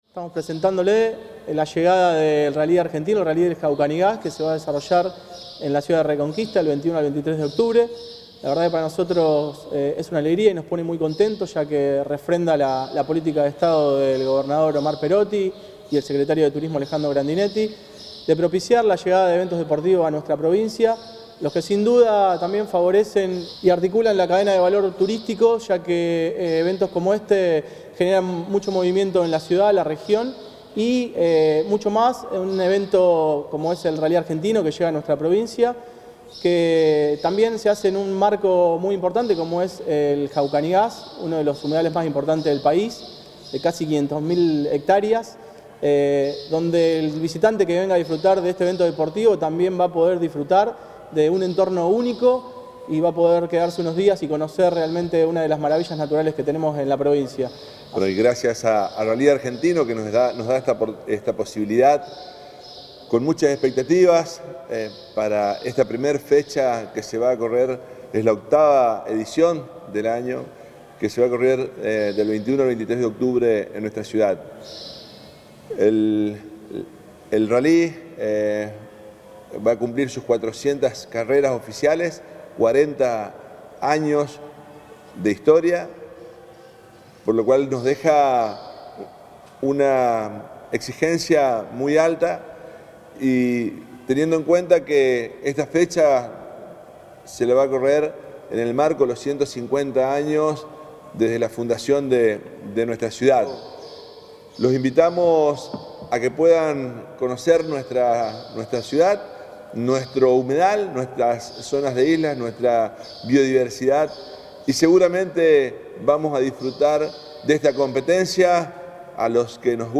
Durante la presentación, llevada a cabo en la Nueva Terminal Fluvial de Rosario, el subsecretario de Promoción y Marketing Turístico de la provincia, Gabriel Kovacevich, manifestó: “Para nosotros este es un evento muy importante, ya que refrenda la política de Estado del gobernador Omar Perotti de posicionar a la provincia turísticamente.